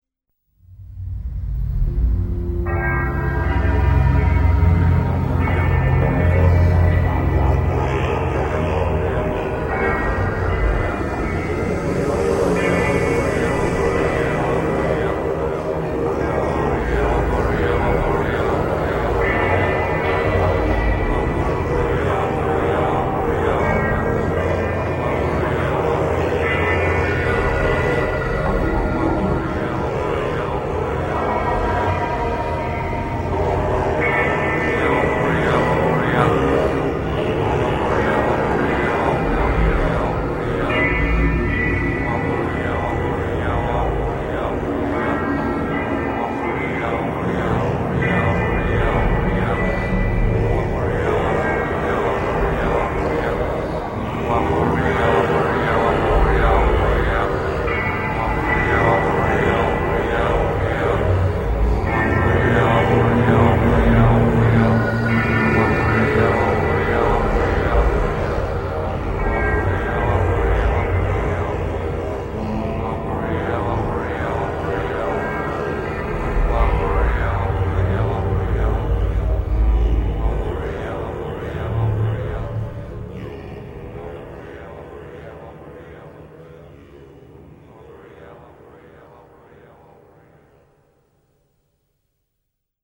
类别:电影原声
这是一段可怖的情境音效。
这段音乐里可以听到魔幻的颂经声，以及隐约的孩童呼唤，表现纯真、罪恶与天谴交织的复杂情绪。